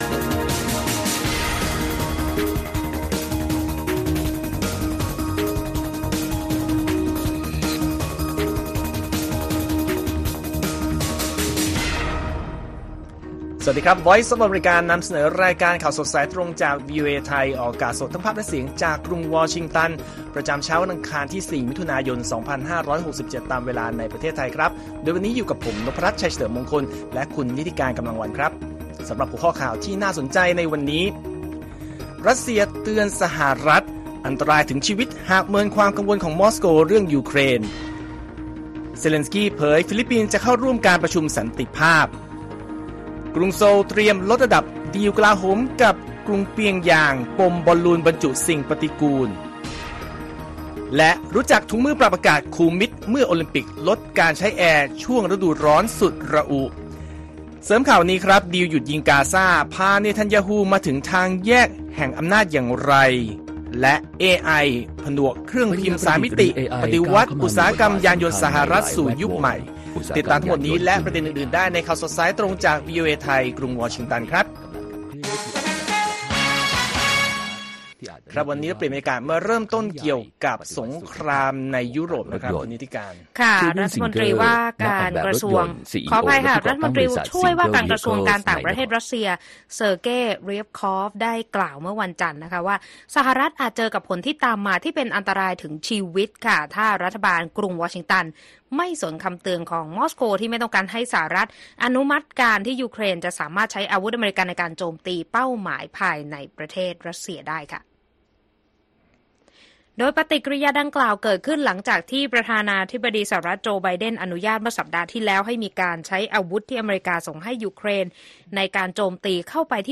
ข่าวสดสายตรงจากวีโอเอ ไทย ประจำวันที่ 4 มิถุนายน 2567